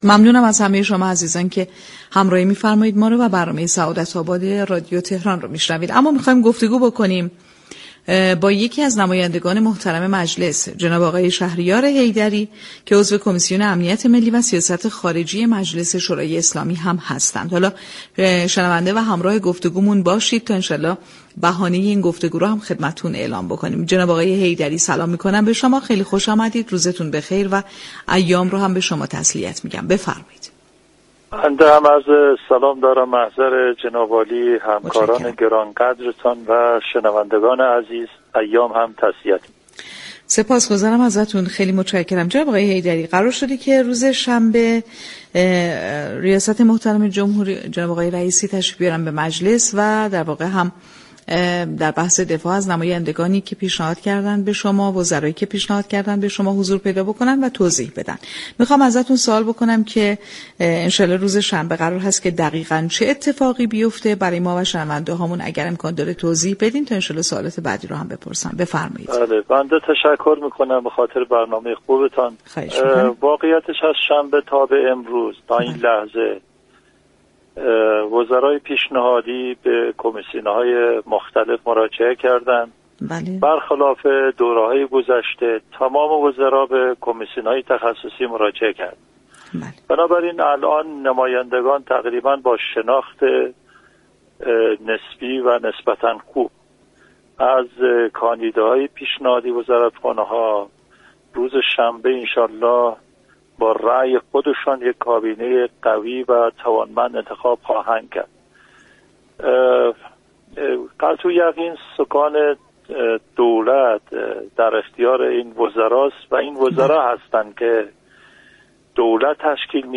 به گزارش پایگاه اطلاع رسانی رادیو تهران، شهریار حیدری عضو كمیسیون امنیت ملی و سیاست خارجی مجلس شورای اسلامی در گفتگو با برنامه سعادت‌آباد رادیو تهران درباره ادامه روند بررسی صلاحیت وزرای پیشنهادی و برگزاری جلسه دفاع در روز شنبه 30 مرداد با حضور رئیس جمهور گفت: از روز شنبه 23 مرداد بر خلاف دوره های گذشته تمامی وزرای پیشنهادی تا به امروز به كمیته های تخصصی مراجعه كرده اند و نمایندگان تقریبا شناخت نسبتا خوبی از آنها دارند .